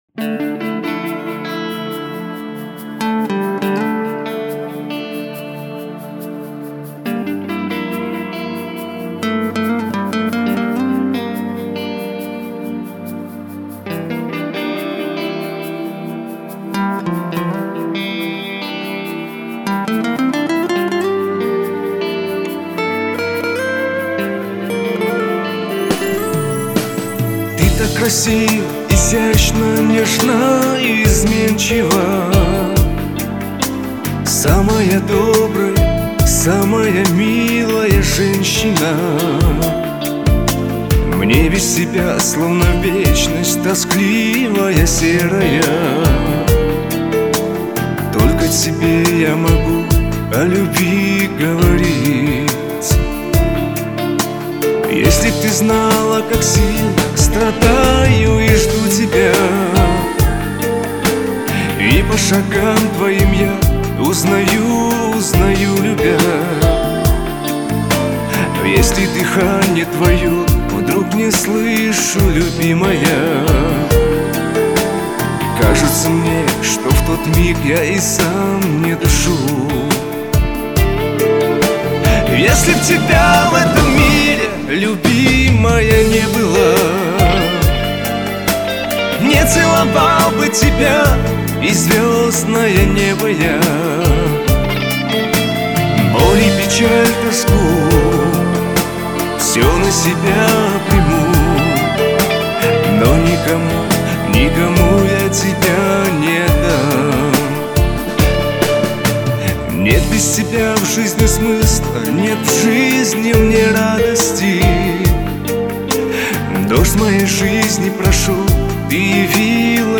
Какие красивые мужественные голоса.....